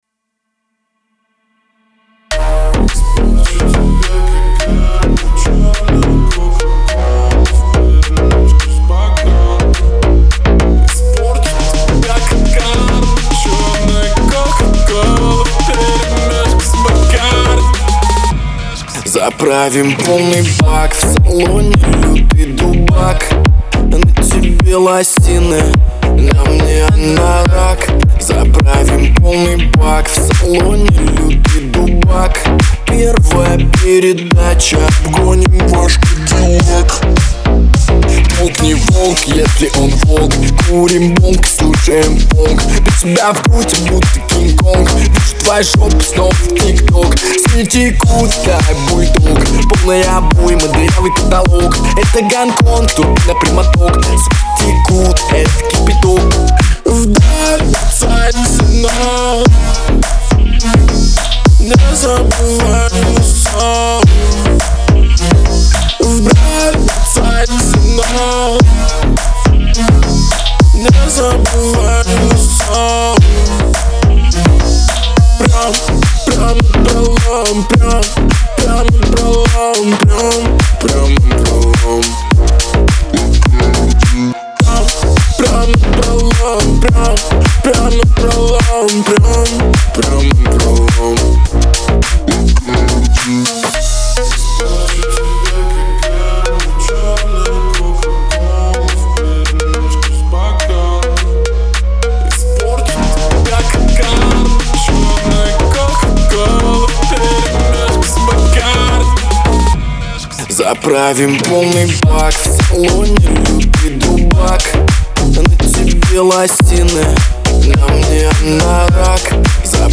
это яркий пример современного русского поп-рока